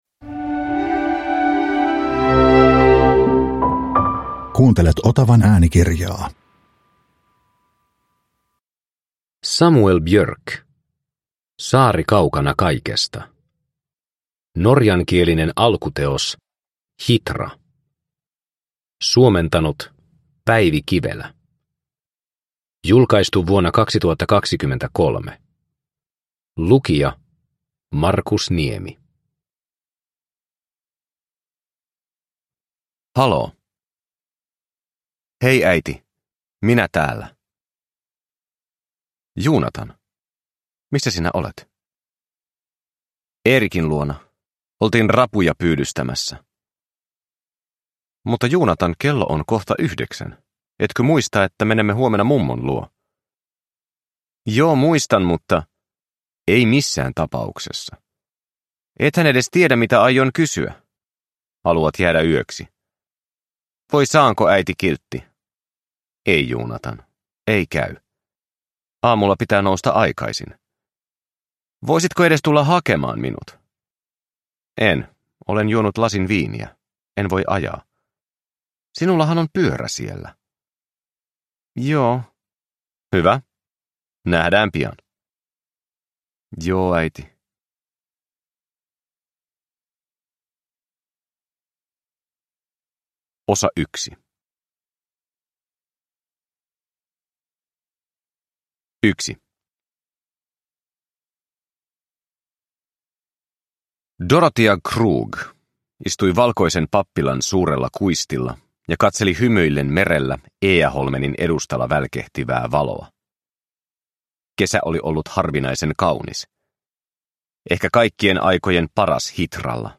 Saari kaukana kaikesta – Ljudbok – Laddas ner